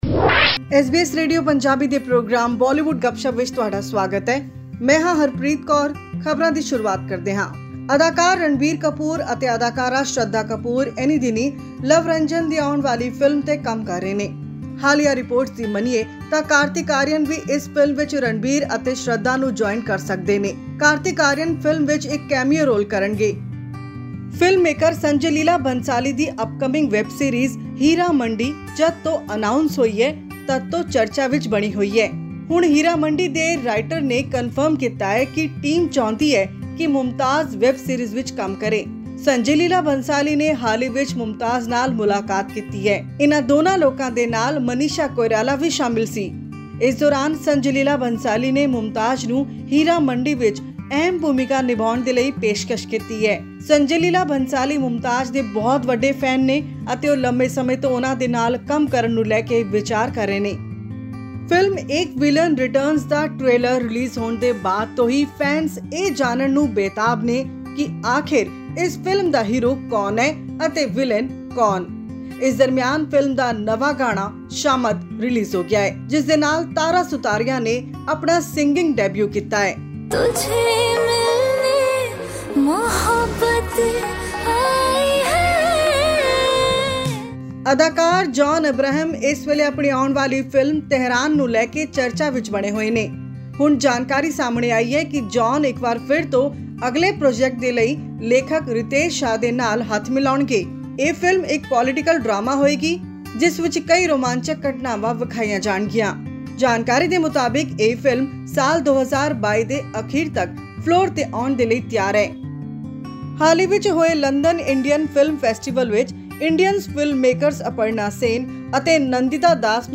A district court of Patiala has upheld two-year jail term of Punjabi pop singer Daler Mehndi awarded by a trial court in 2018. After the decision by the court, he was arrested and sent to Patiala prison. Listen to our weekly Bollywood news bulletin for more details...